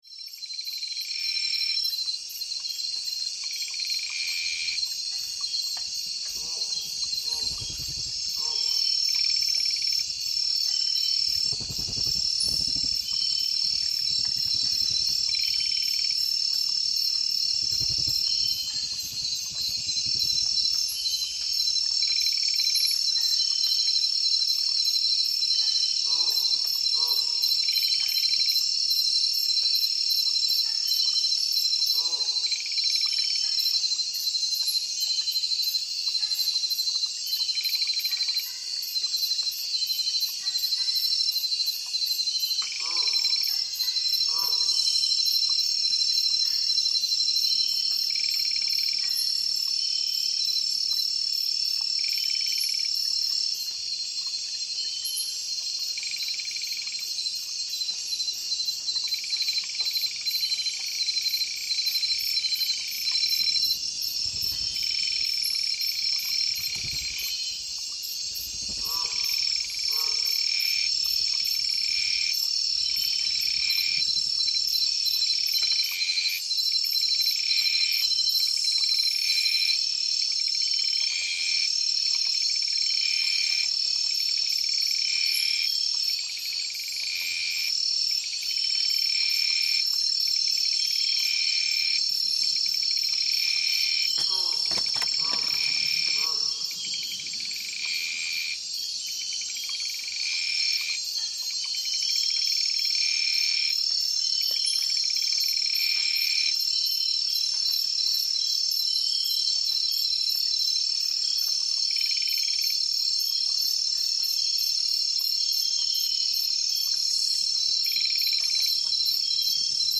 A nighttime recording from the Ecuadorian Amazon reveals the hidden world that awakens after dark. Bats flutter overhead, insects create an otherworldly symphony, and distant howls echo through the trees. A gentle stream murmurs beneath it all, weaving a tapestry of life, survival, and mystery.